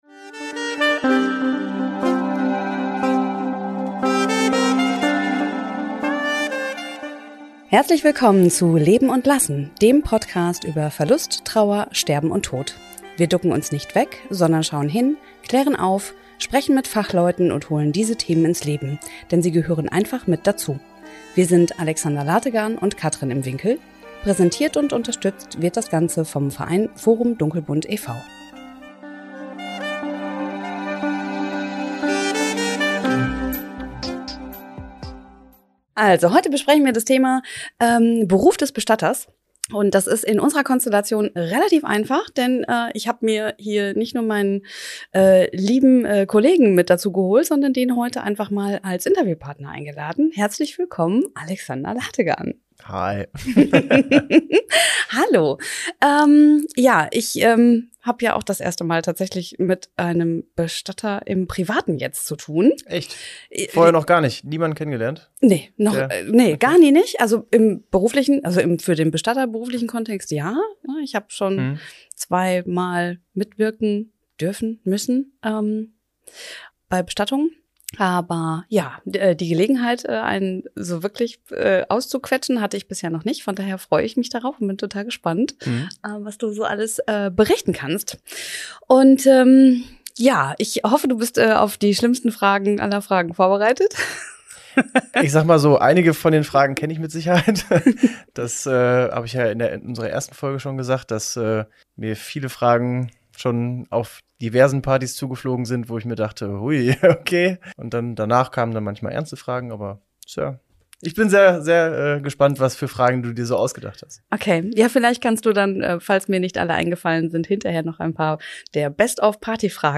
#005 - Interview mit einem Bestatter ~ Leben und Lassen Podcast